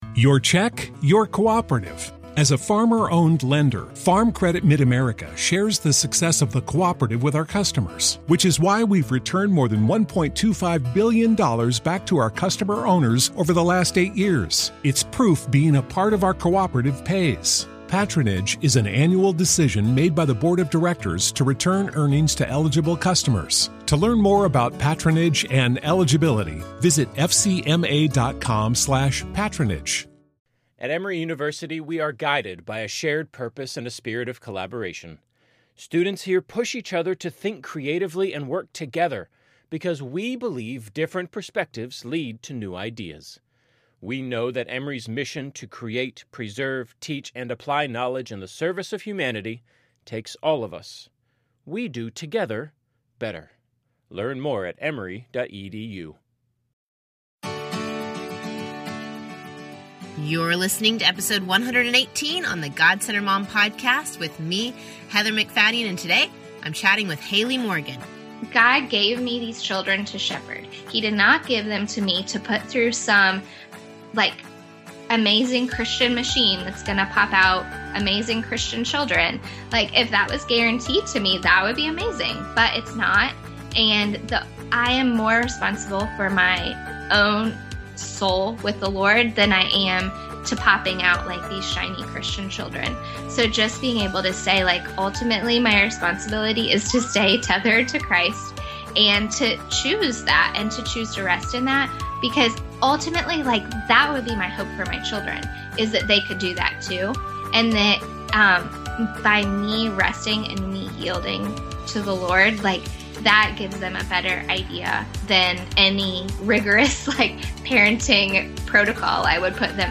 interviews guests discussing the topic of staying God-centered...both replacing "me" with "He" and remembering we are centered in Him.